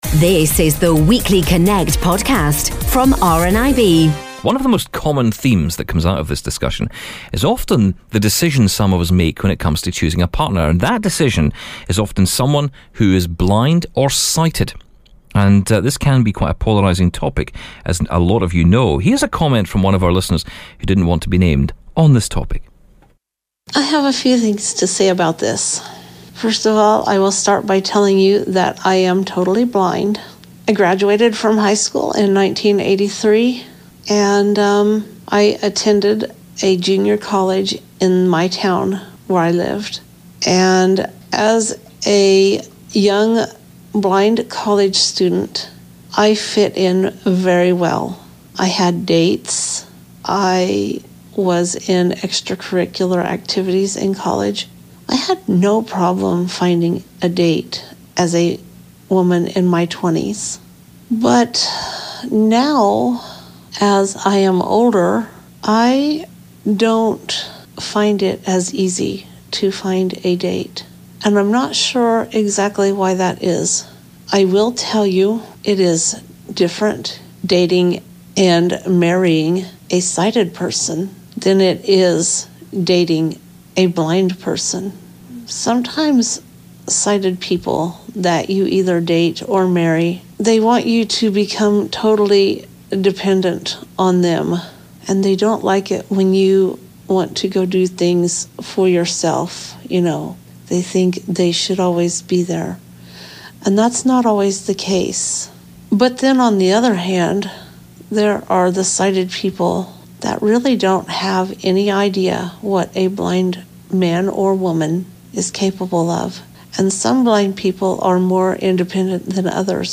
Listen to one of our listeners views on blind dating.